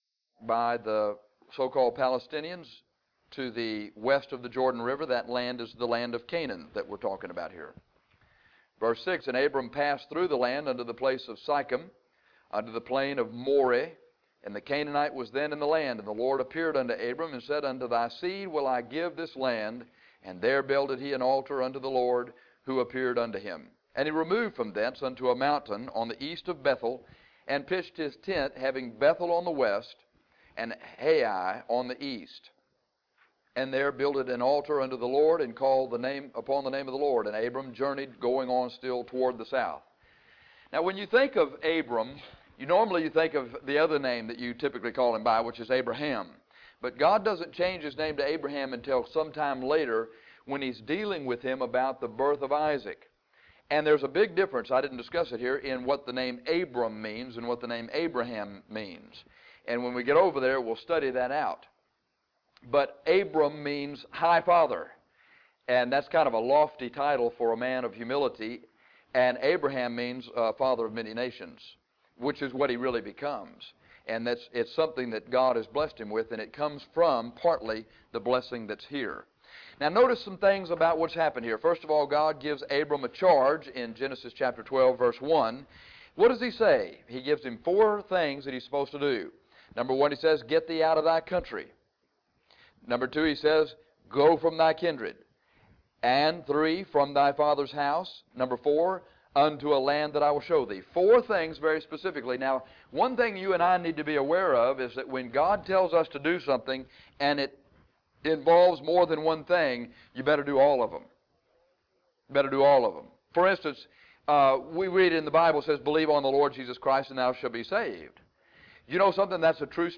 In this lesson, we find that God gave Abram a charge to leave his own country and go to a land that he would give Abram and his descendants for an inheritance. Abram obeyed God’s charge to leave, but he disobeyed God’s command to leave his family behind.